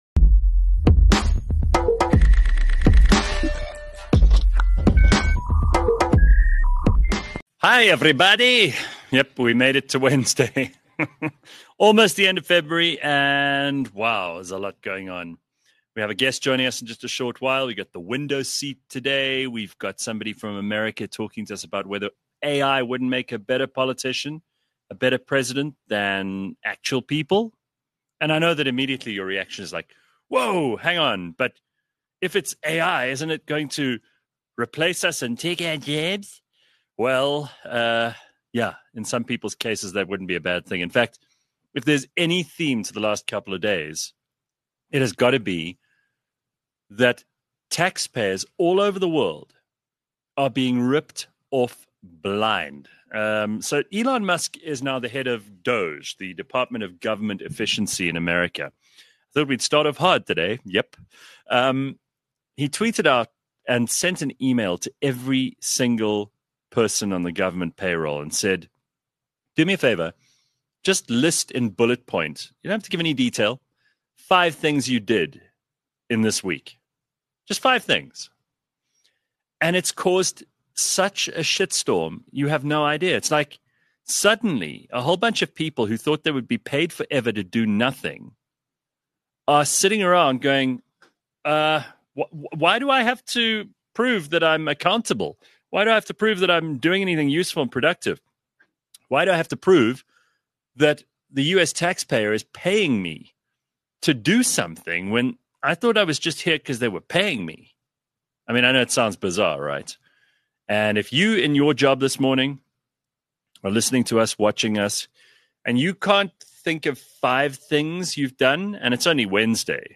in-depth chat